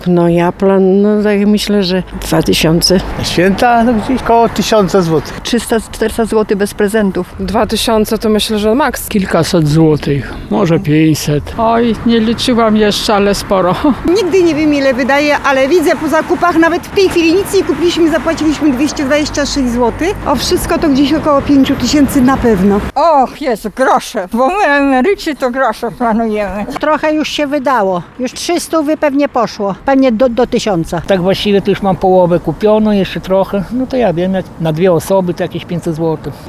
Więcej niż przed rokiem zamierzają wydać na święta osoby, które spotkaliśmy na ulicach Suwałk.